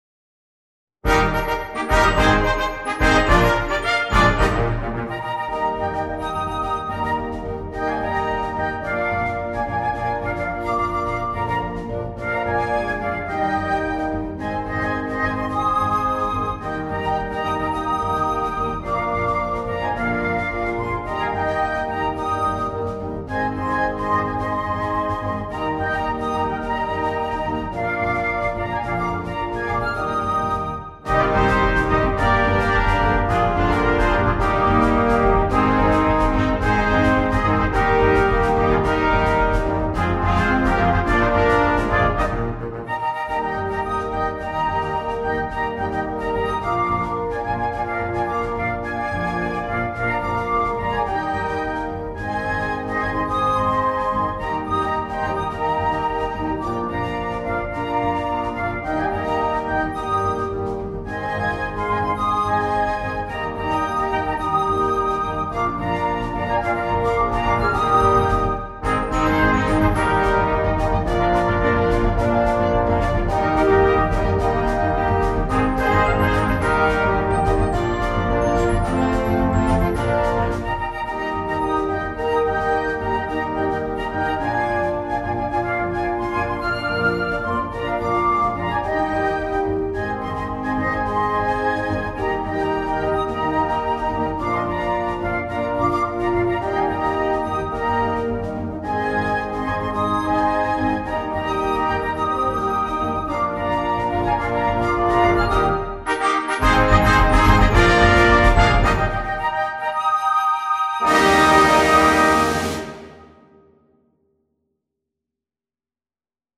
mit Soloinstrument
feat. Flöte
Unterhaltung